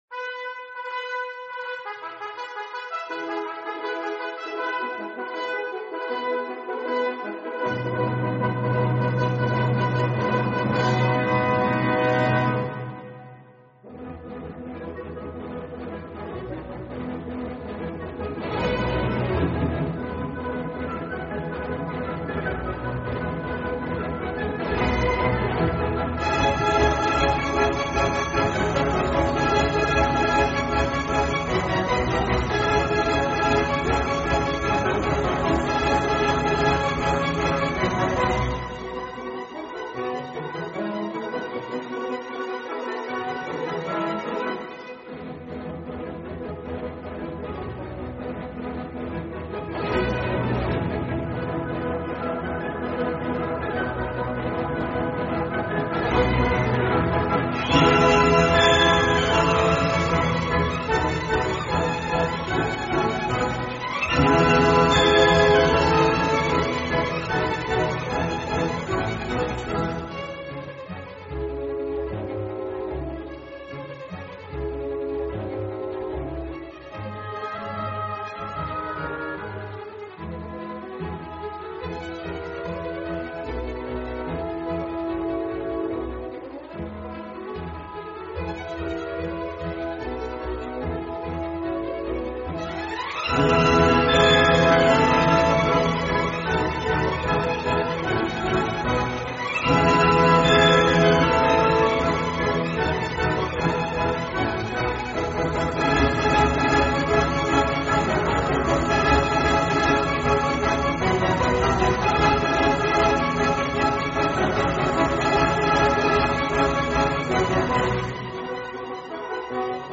The Most Famous Classical Music Pieces of All Time.mp3